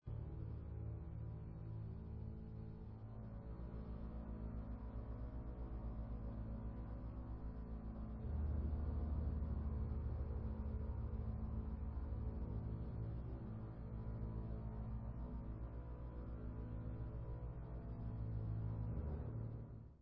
Symphonic Poem
sledovat novinky v kategorii Vážná hudba